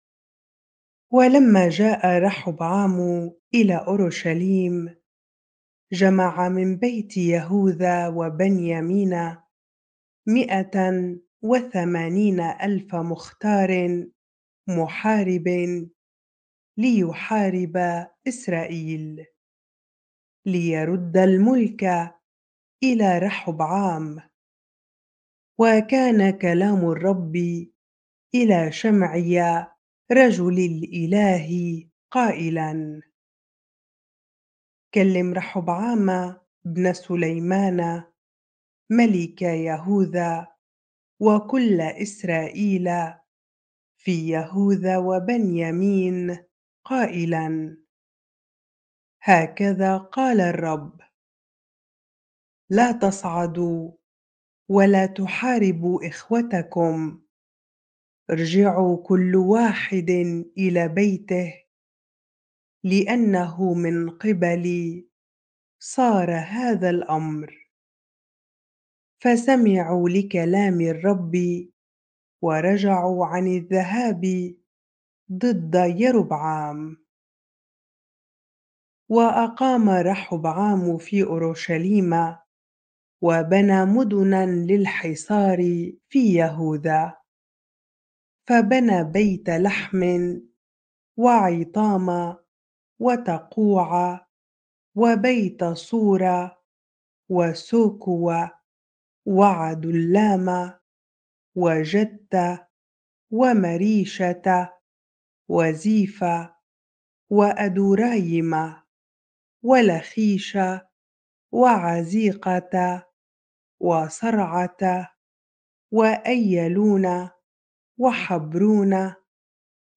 bible-reading-2 Chronicles 11 ar